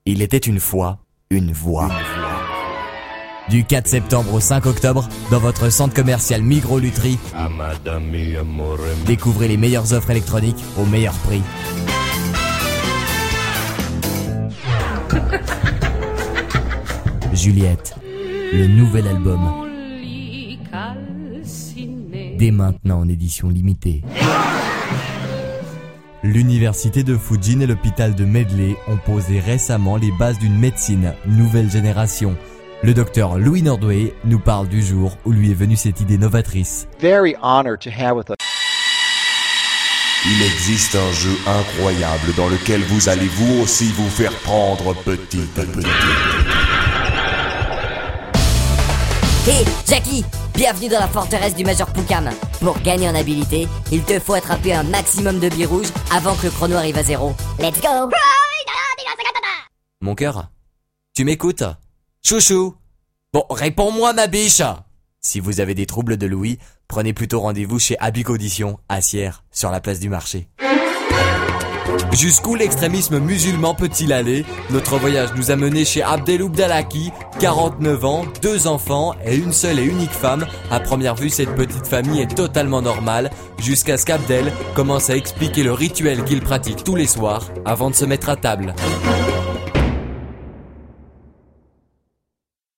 Animateur radio et voix off caméléon en Suisse romande.
Voix off suisse romande
Sprechprobe: Werbung (Muttersprache):